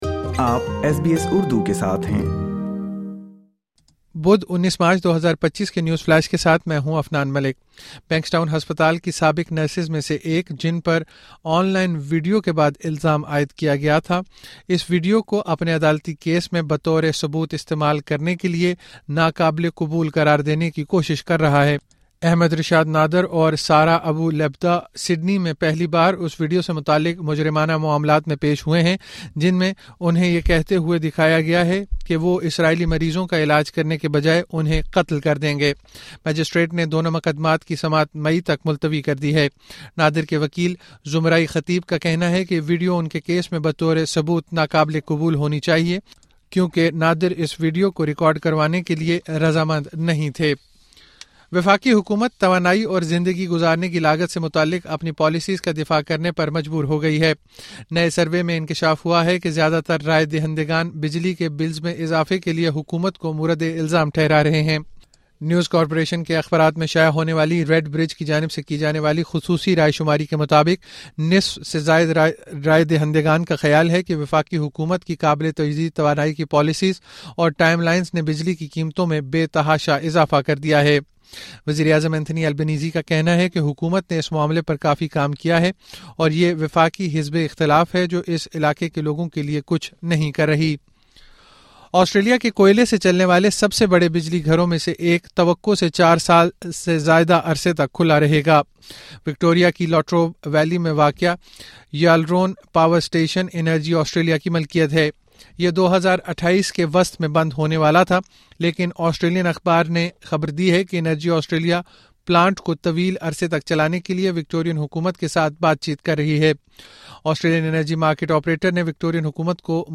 مختصر خبریں: منگل 19 مارچ 2025